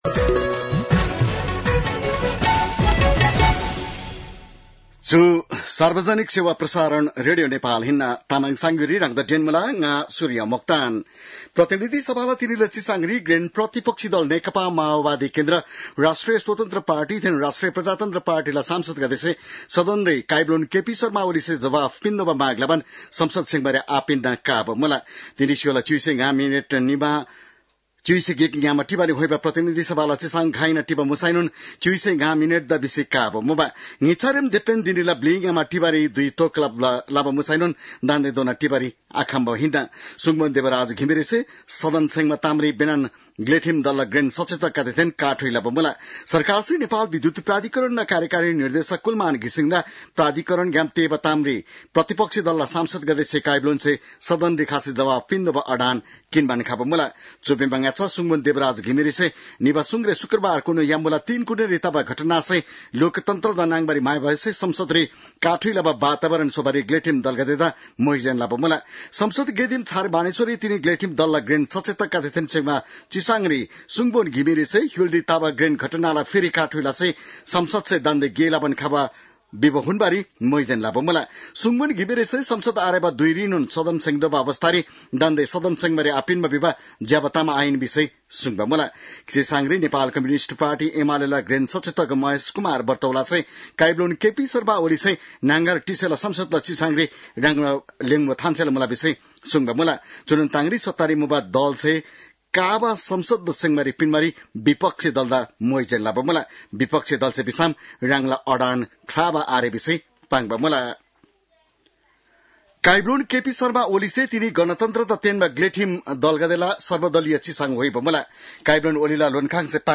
तामाङ भाषाको समाचार : १७ चैत , २०८१